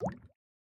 drip_lava1.ogg